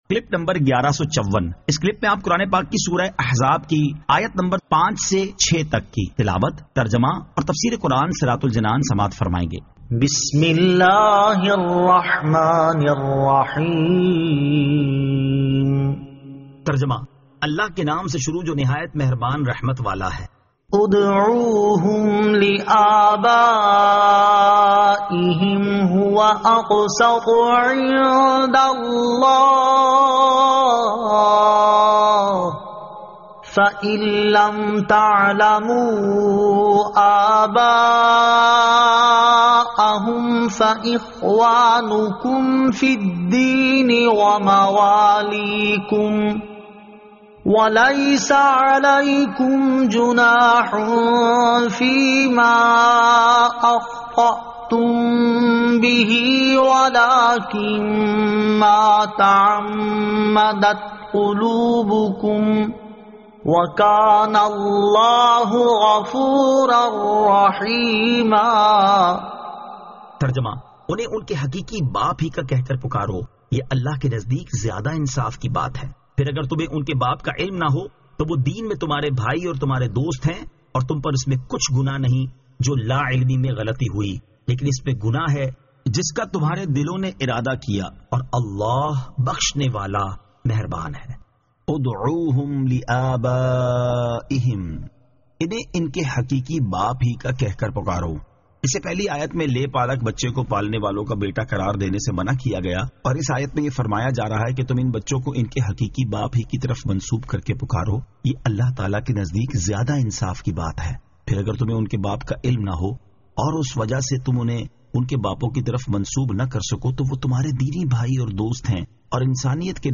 Surah Al-Ahzab 05 To 06 Tilawat , Tarjama , Tafseer